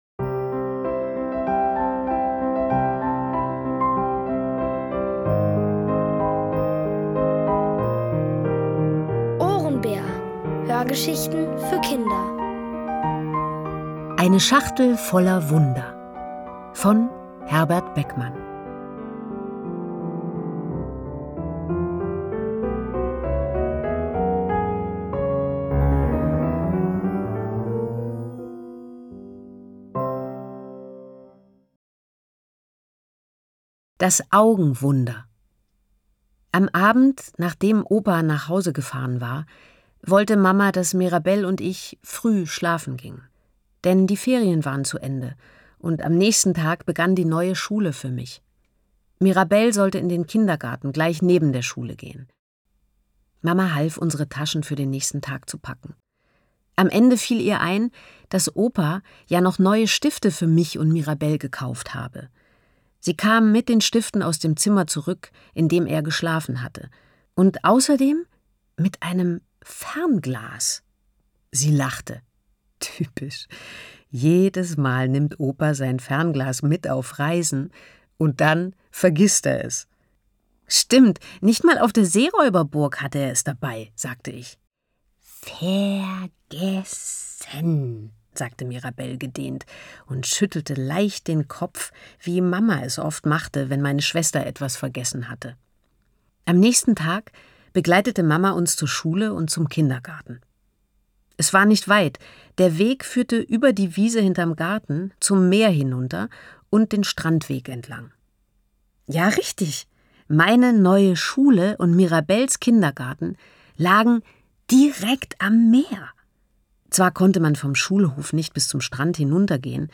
Von Autoren extra für die Reihe geschrieben und von bekannten Schauspielern gelesen.
Es liest: Nina Hoss.